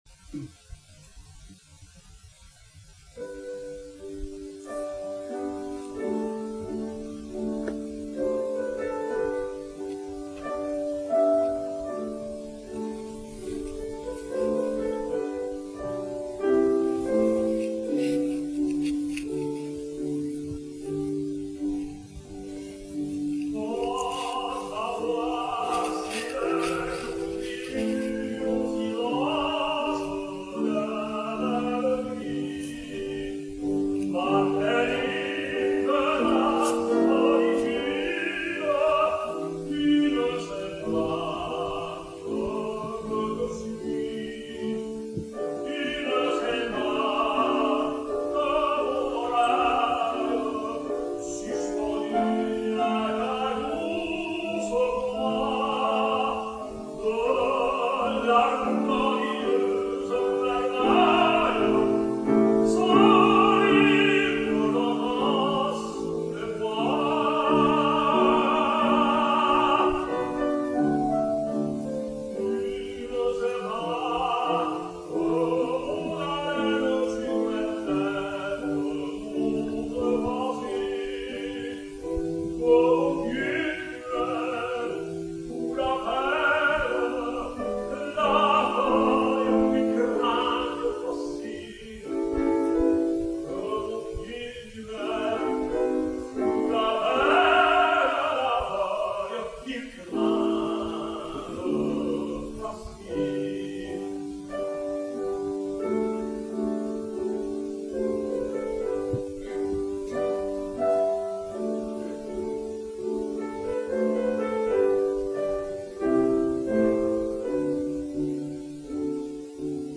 Liederabend
Nicolai Gedda, Tenor
Klavier